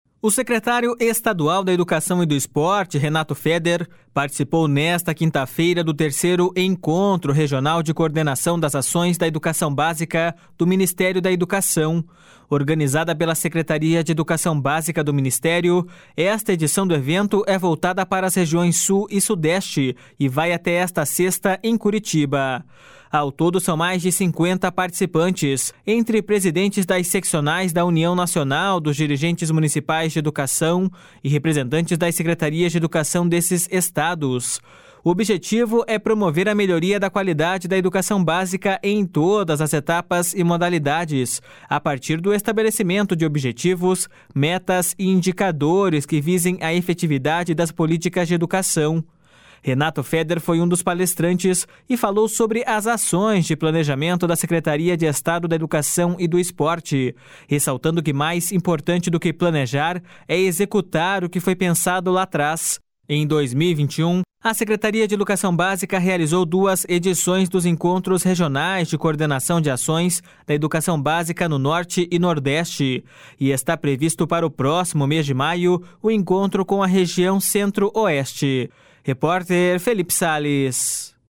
O secretário estadual da Educação e do Esporte, Renato Feder, participou nesta quinta-feira do 3º Encontro Regional de coordenação das ações da educação básica do Ministério da Educação.